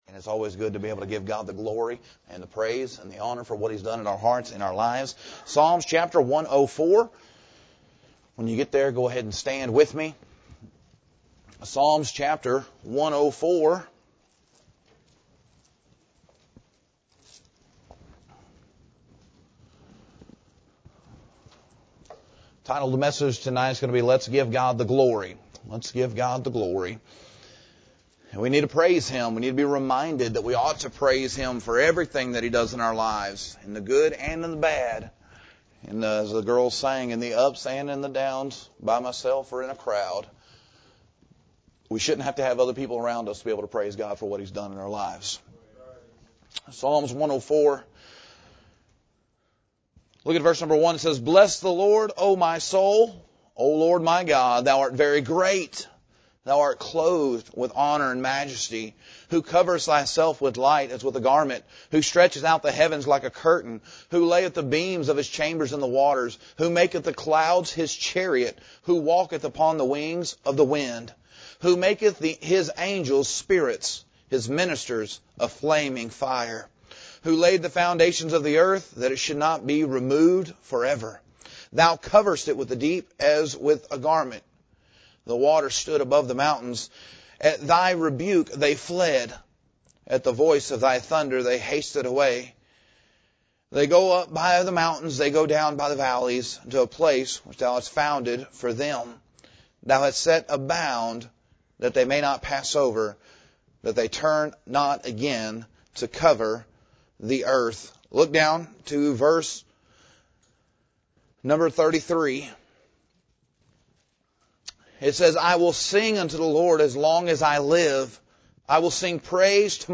Quote from Preacher And it’s always good to be able to give God the glory and the praise and the honor for what he’s done in our hearts, in our lives.